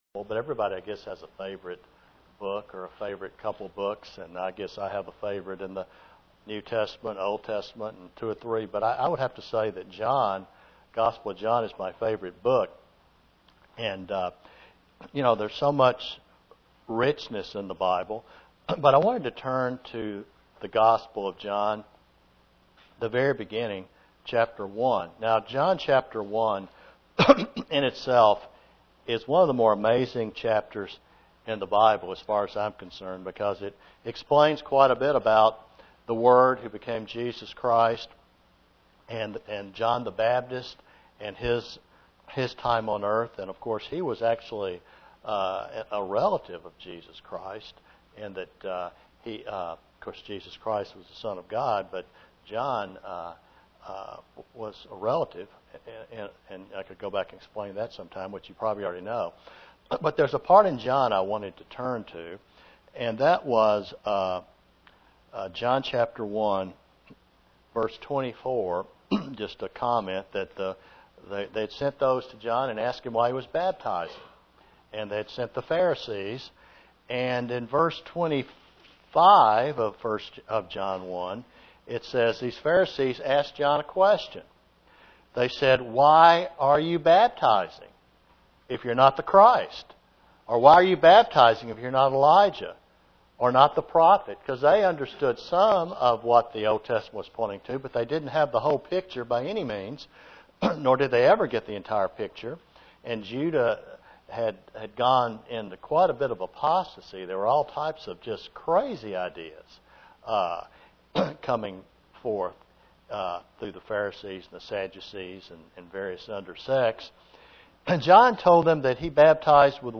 As we approach Passover and the service to honor our savior, each should ask themselves what does the life of Jesus Christ mean to them. (Presented to the Knoxville TN, Church)